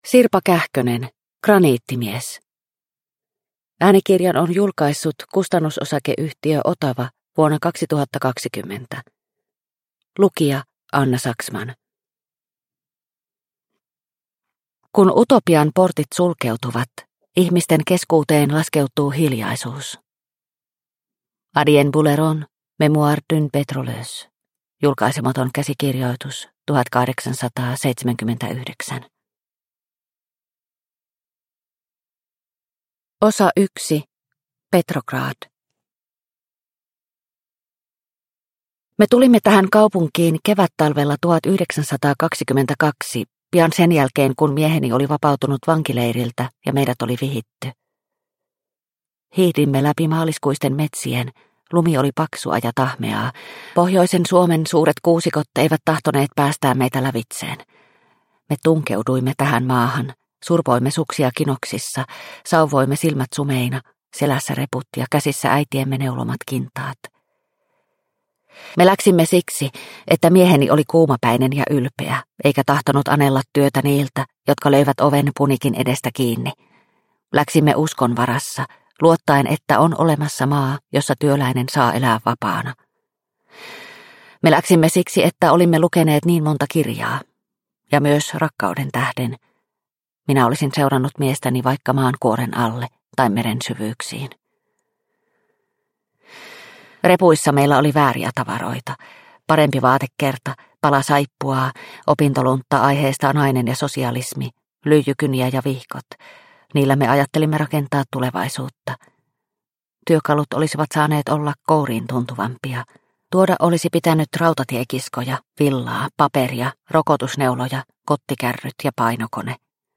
Graniittimies – Ljudbok – Laddas ner